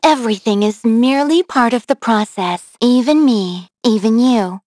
Xerah-Vox_Victory_b.wav